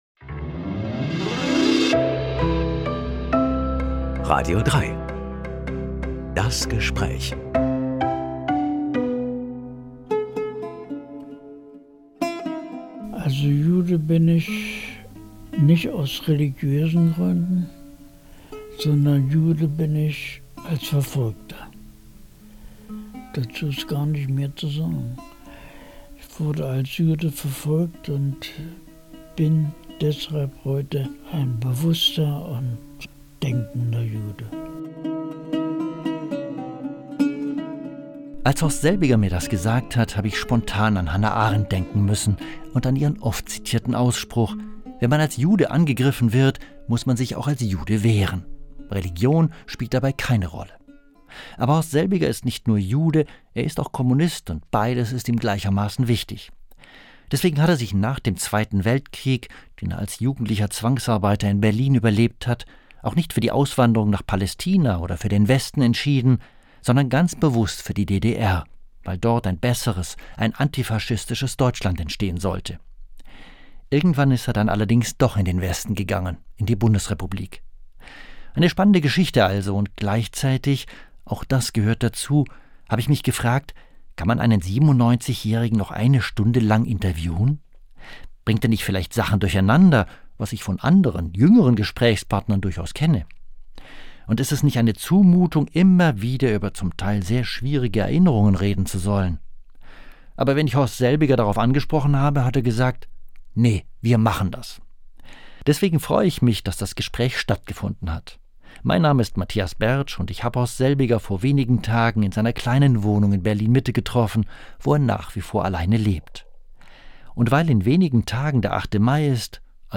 Reden mit einem Menschen. Eine knappe Stunde lang.